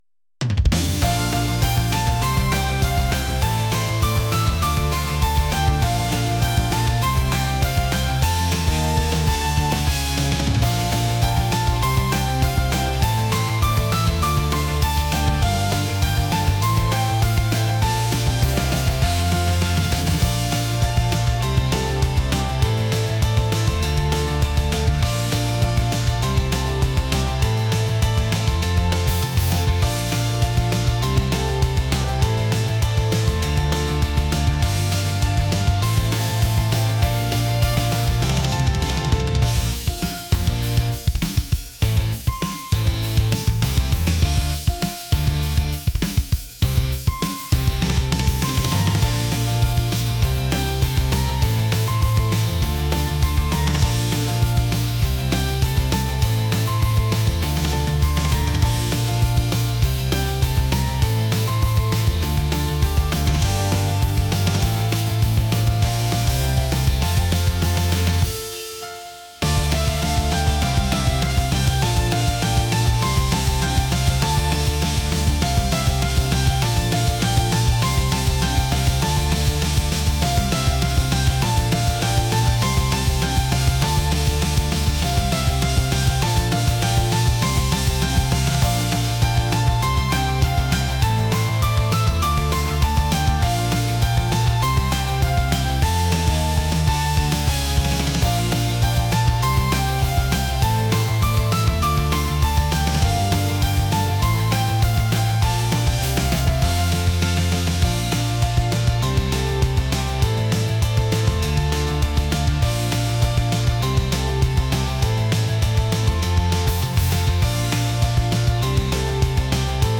upbeat | pop